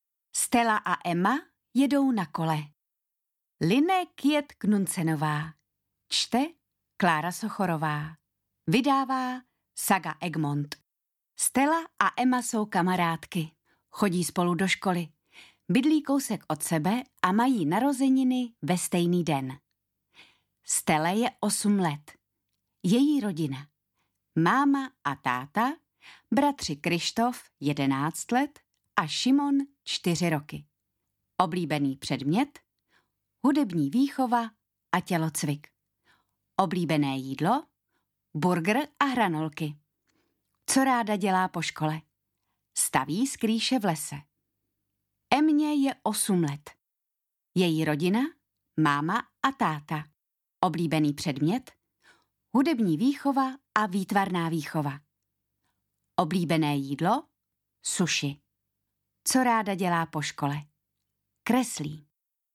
Ukázka z knihy
stella-a-ema-jedou-na-kole-audiokniha